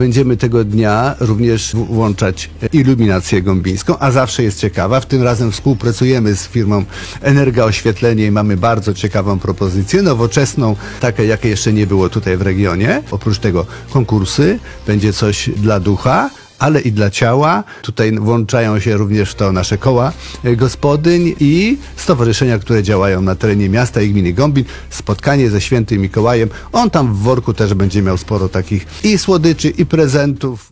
– mówił Burmistrz Miasta i Gminy Gąbin Krzysztof Jadczak.